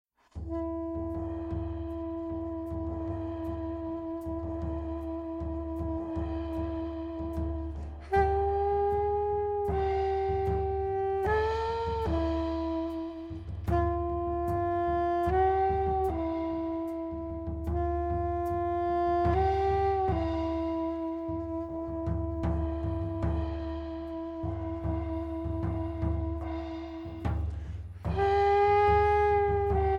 Guitar
Soprano Saxophone
Piano
Bass
Drums